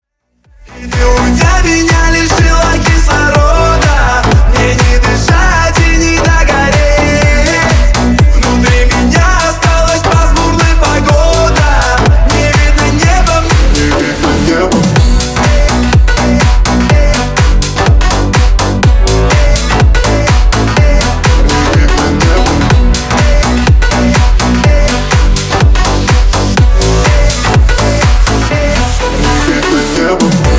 • Качество: 320, Stereo
Club House
очень громкие